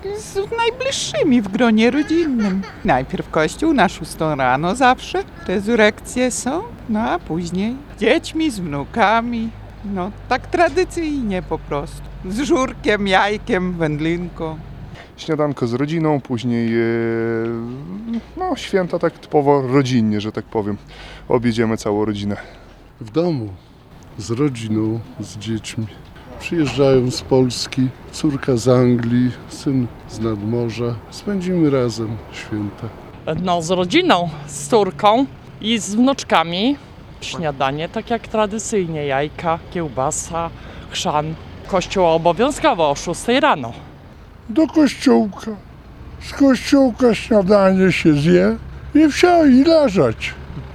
Tak przynajmniej wynika z sondy przeprowadzonej przez naszego reportera na ulicach miasta. Nieodłącznymi elementami Wielkanocy są według suwalczan wspólne śniadanie, uczestnictwo we mszy rezurekcyjnej i spotkania z najbliższymi przy stole.
jak-spędzamy-święta-wielkanocne-sonda.mp3